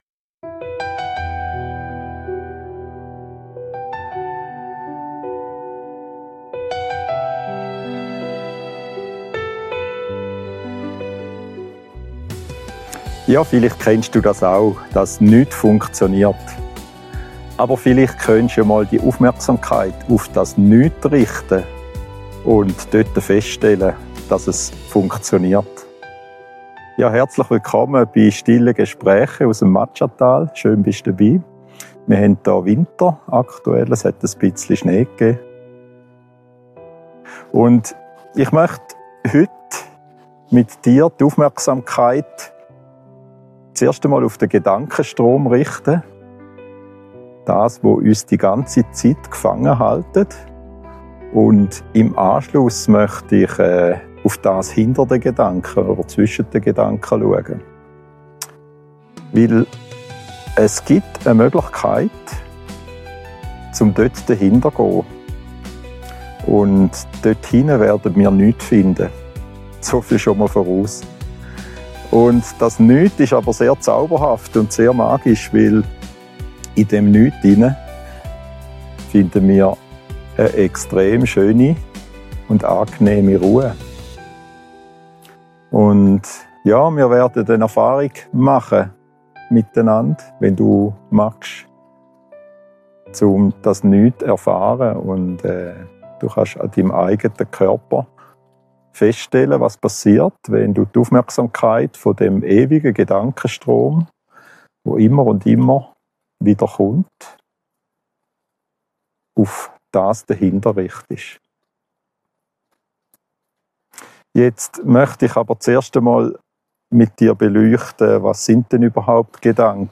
Schweizerdeutsch gesprochen.